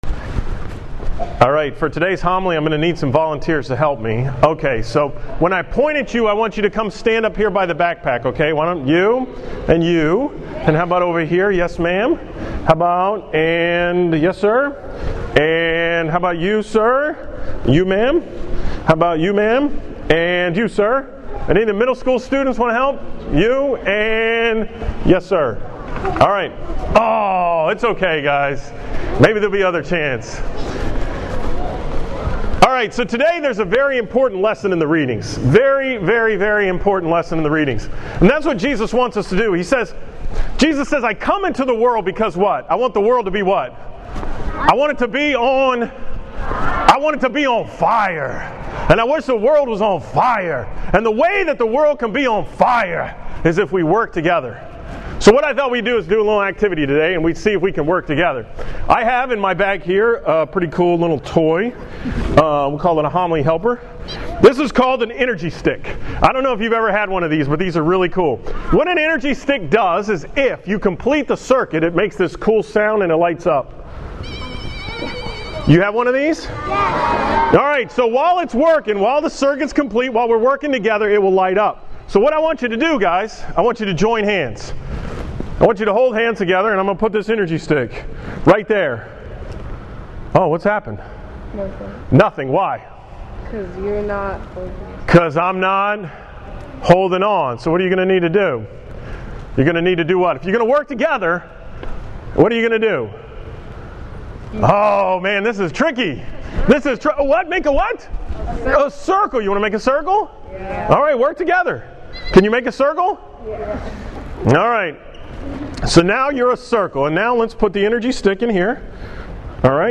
From the school Mass at St. John Paul II school